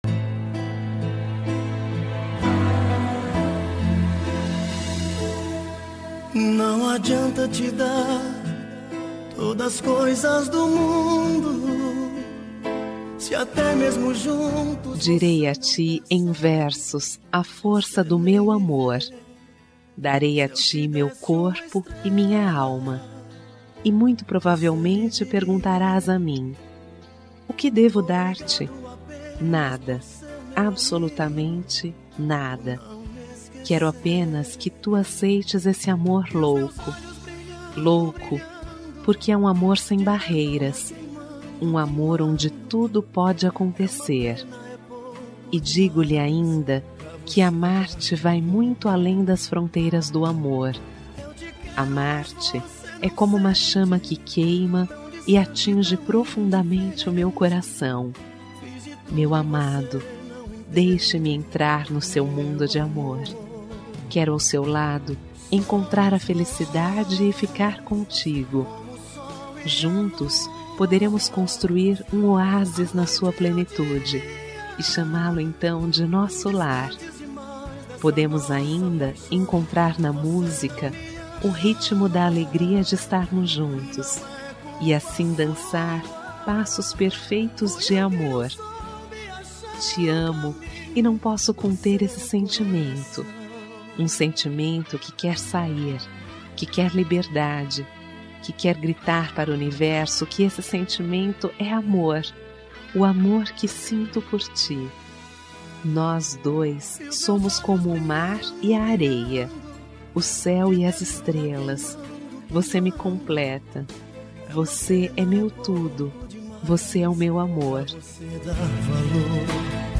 Romântica para Marido – Voz Feminina – Cód: 350333